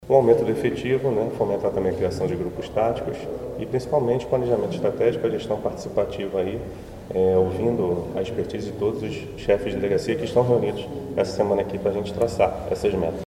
Em coletiva na manhã desta sexta-feira, 9, o superintendente Regional da PRF em MG, Marco Antônio Territo de Barros, explicou sobre as ações planejadas para a região.
superintendente Regional da PRF Marco Antônio Territo de Barros